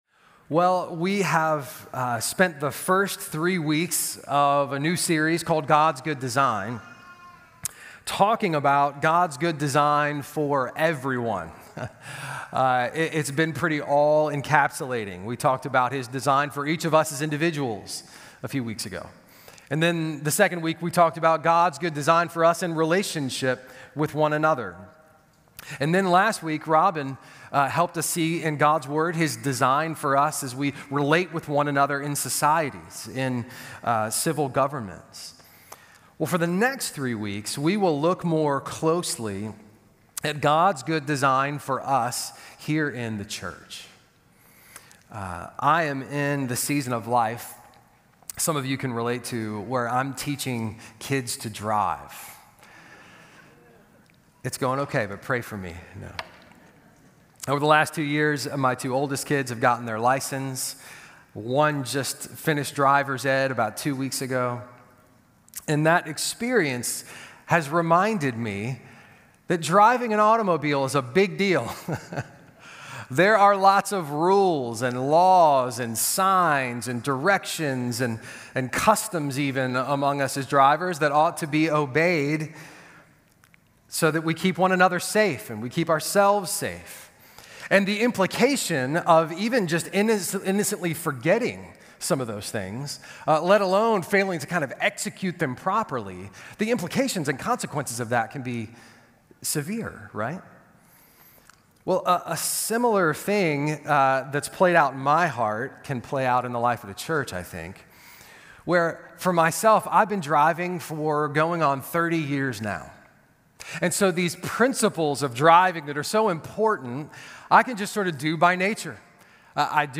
All Sermons Watch Message Download MP3